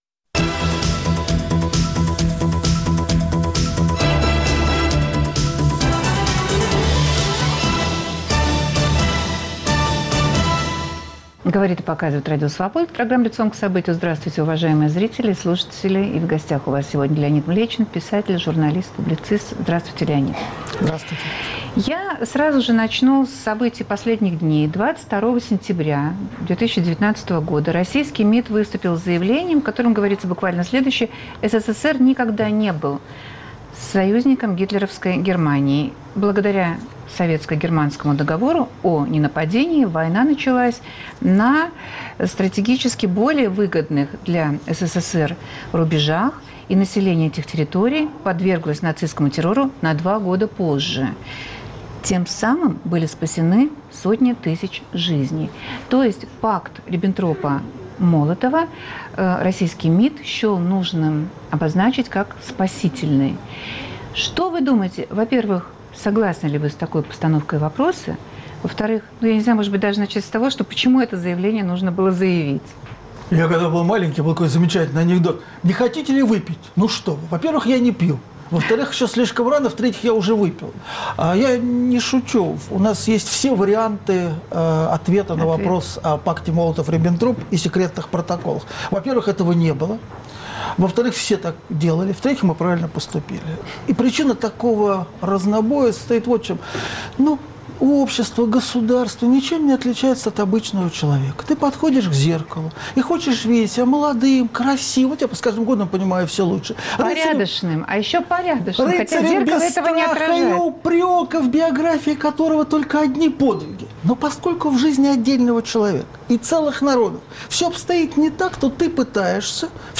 Какой была роль союзников в разгроме фашизма? В эфире писатель Леонид Млечин.